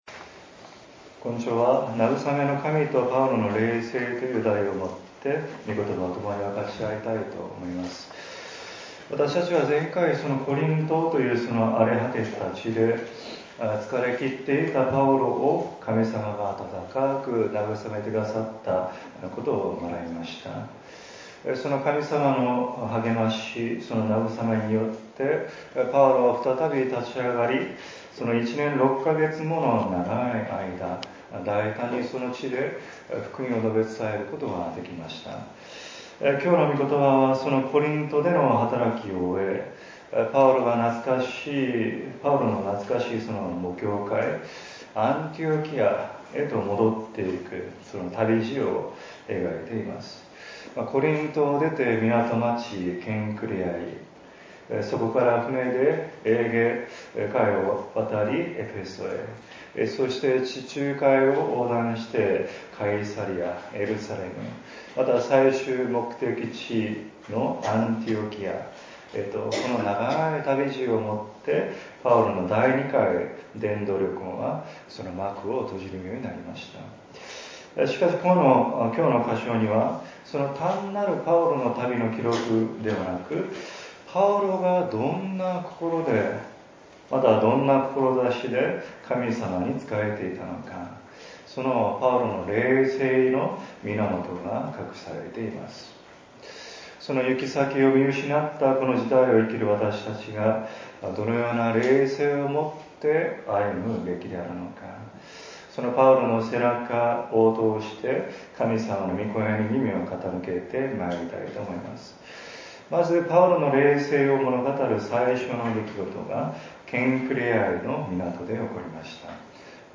礼拝次第